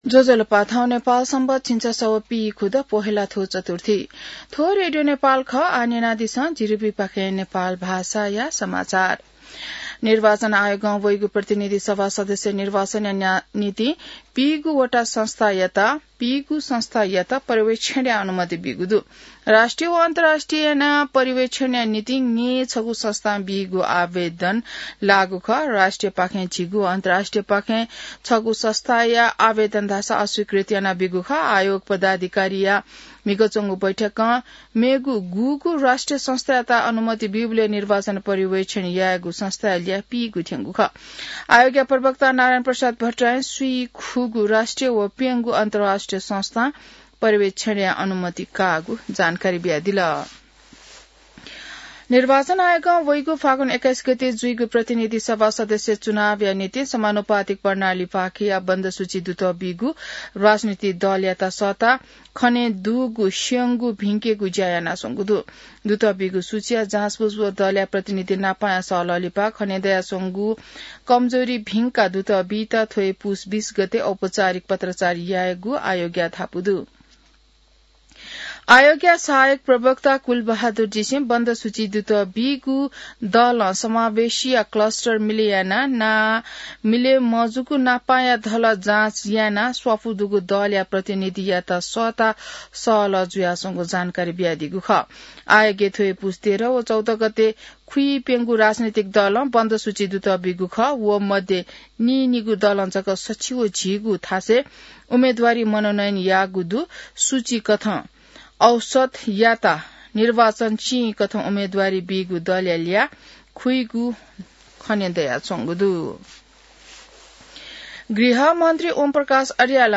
नेपाल भाषामा समाचार : १८ पुष , २०८२